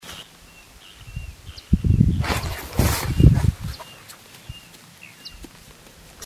Inambú Montaraz (Nothoprocta cinerascens)
Nombre en inglés: Brushland Tinamou
Localidad o área protegida: Reserva de Biósfera Ñacuñán
Condición: Silvestre
Certeza: Vocalización Grabada
Inambu-Montaraz.mp3